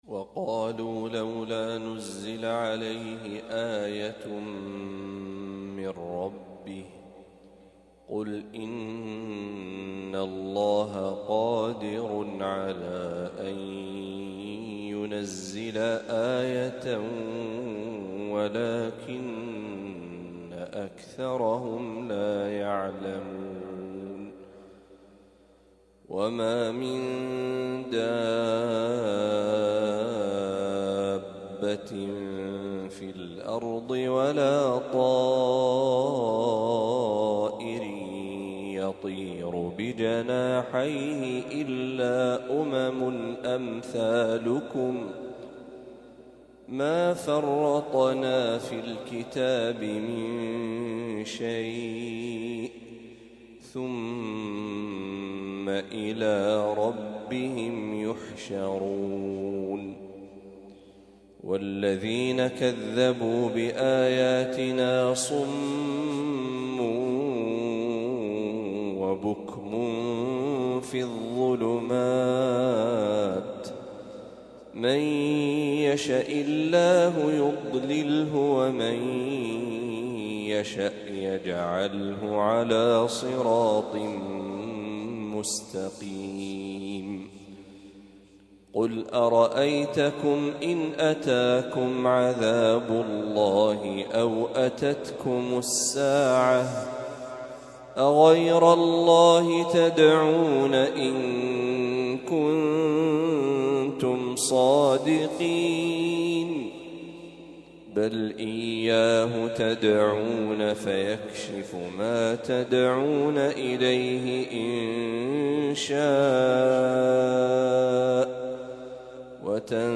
ما تيسر من سورة الأنعام | فجر الخميس ١٦ ربيع الأول ١٤٤٦هـ > 1446هـ > تلاوات الشيخ محمد برهجي > المزيد - تلاوات الحرمين